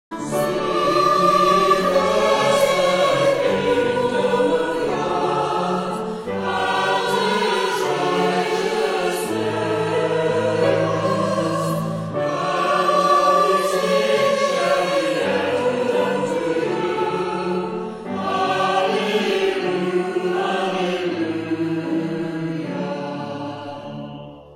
Maybole Churches have a long history of Choral Singing.
Praise music performed in these historic buildings.